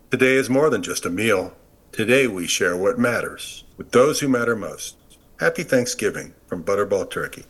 Commercial - Butterball Turkey
USA English, midwest
Middle Aged
I work from a broadcast-quality home studio.